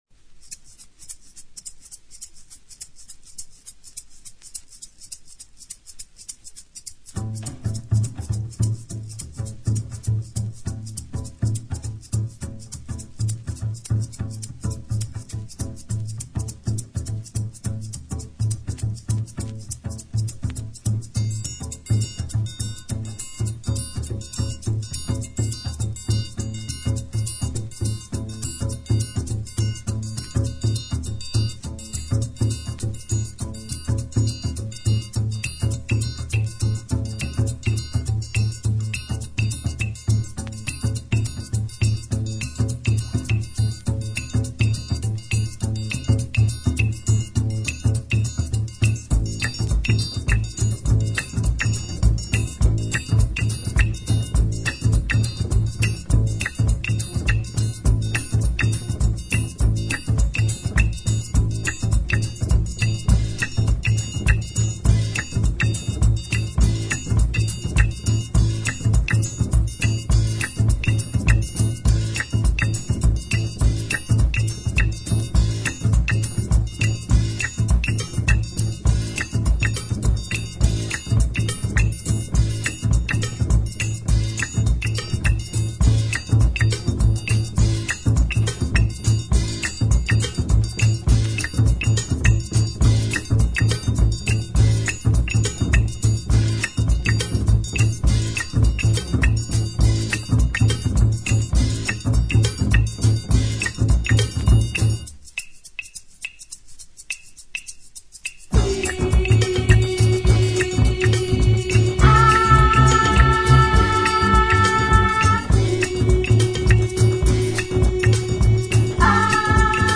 [ JAZZ | ROCK | FUNK | WORLD ]